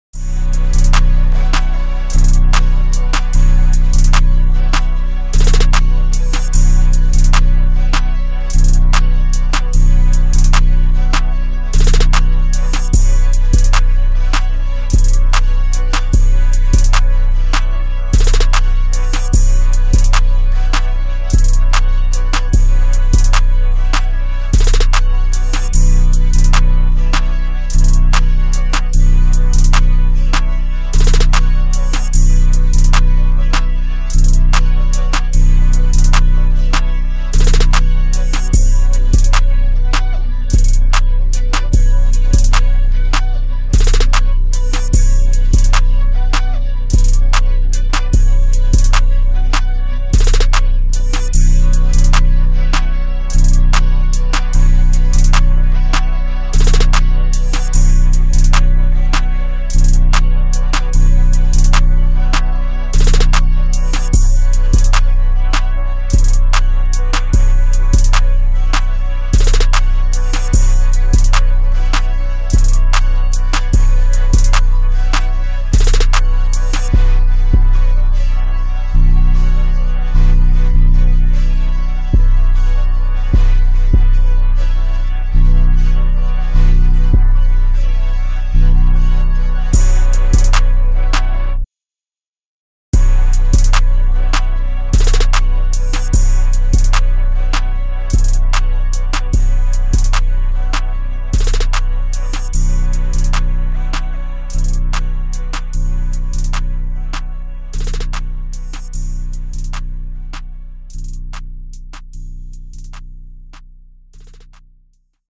catchy rhythms and a fun beat